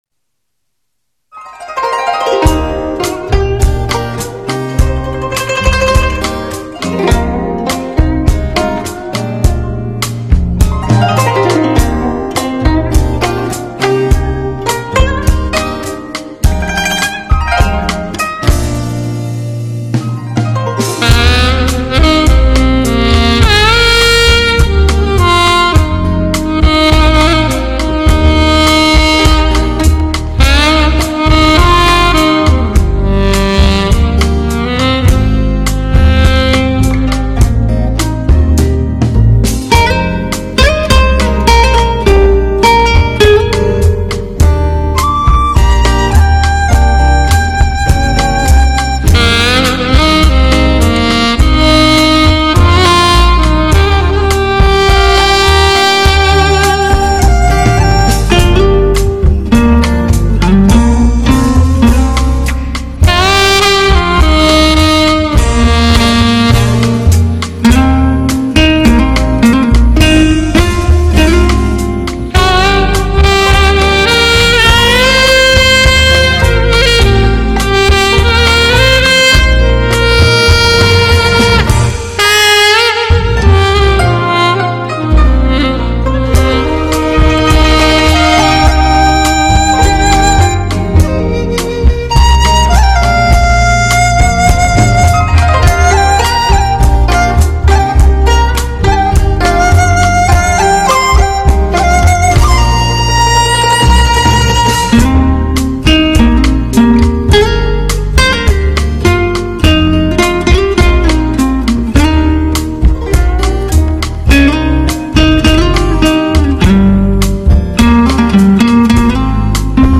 SAX
GUITAR
纯音乐HiFi发烧碟
不带人声杂质的独享滋味，就这样不经意流淌过清脆的声线。